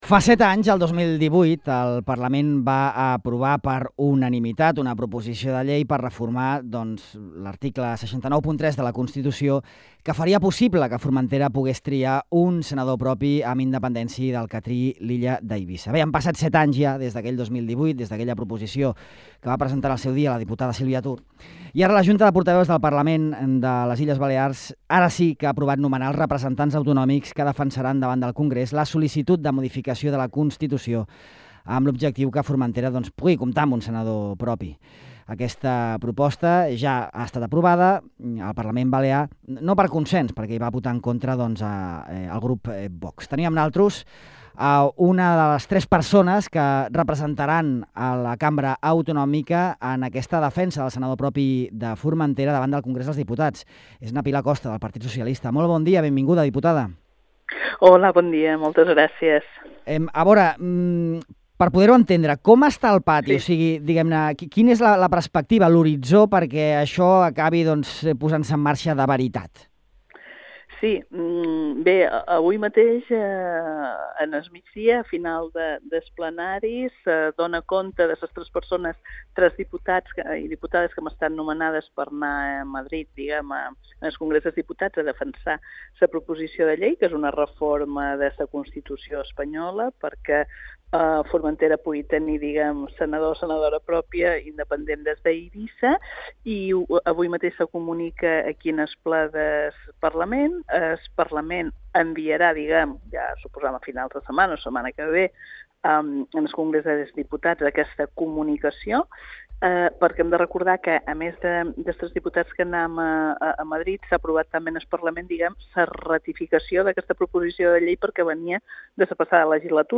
Els tres parlamentaris han declarat a Ràdio Illa que confien que aquesta reforma constitucional podrà ser tramitada, debatuda i aprovada durant el 2026 i, en tot cas, abans que acabi la present legislatura, el 2027.